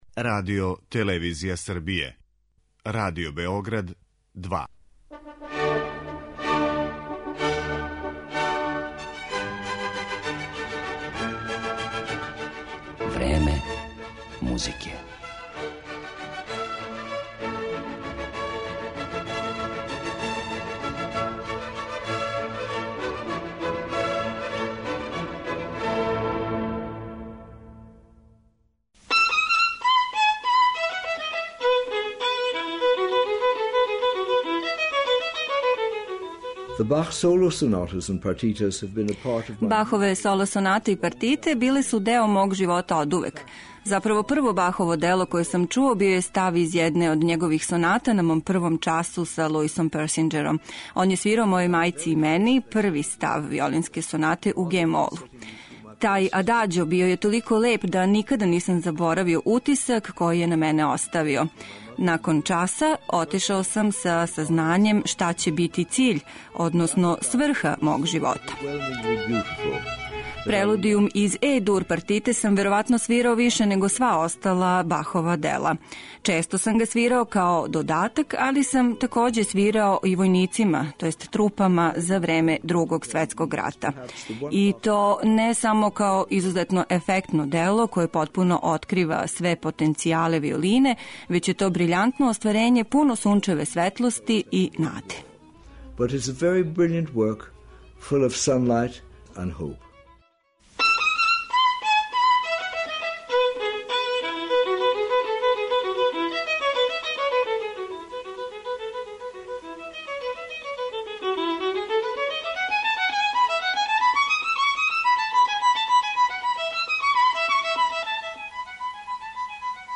Своју је музику несебично делио током дуге и успешне каријере широм света: Свирам за све оне који су гладни и жедни музике, али и за оне који пате, без обзира на боју коже и порекло - речи су лорда Мењухина, чији ће портрет бити осликан музиком Баха, Елгара, Бартока...